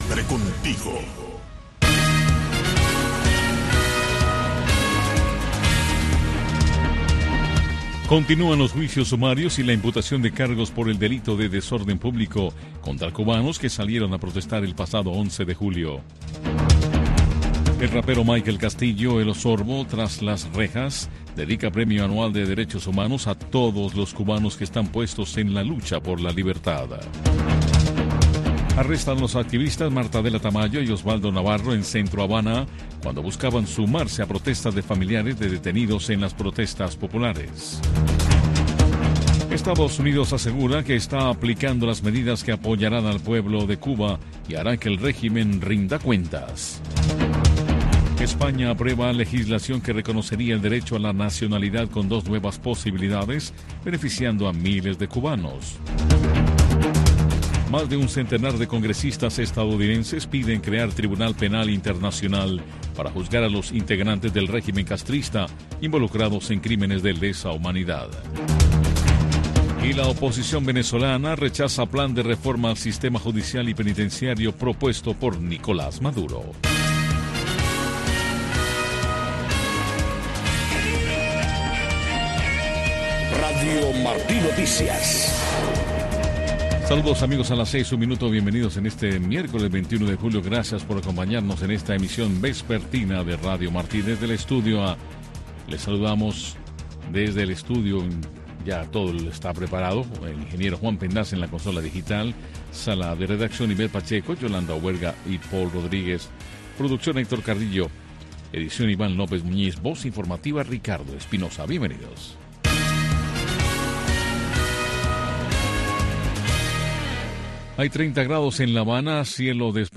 Noticiero de Radio Martí 6:00 PM